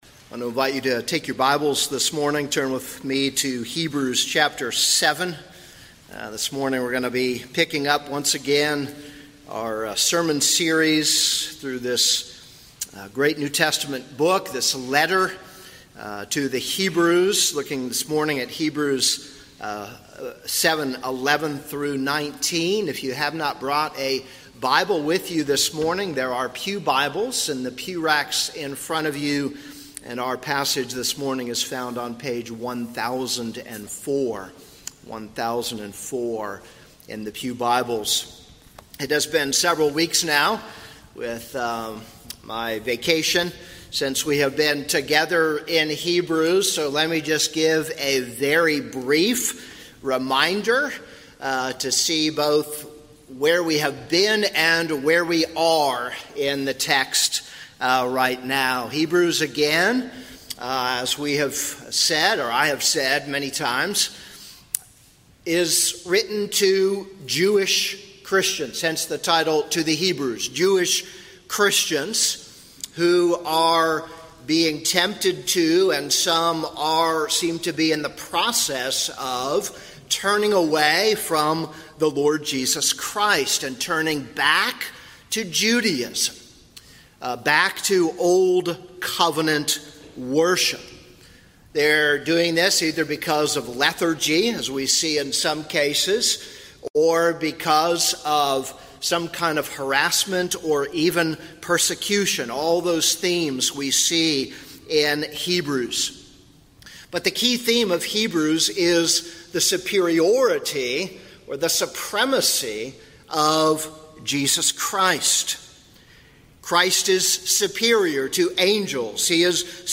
This is a sermon on Hebrews 7:11-19.